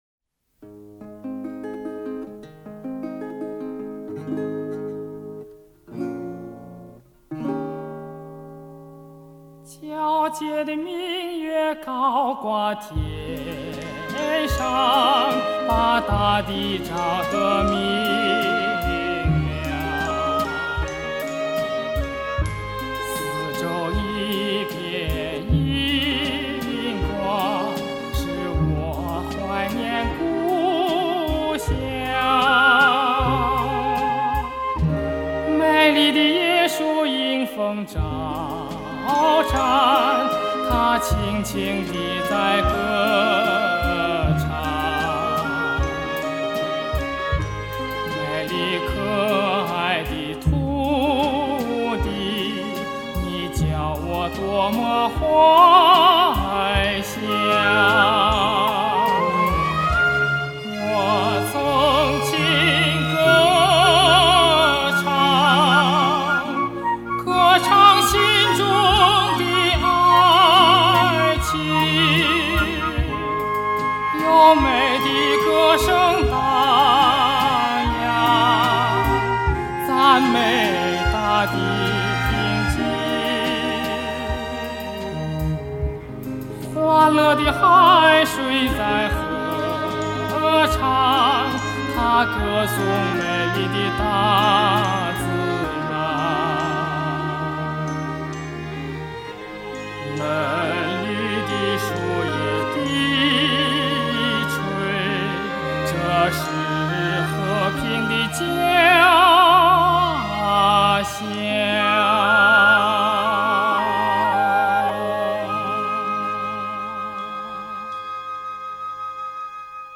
印度尼西亚民歌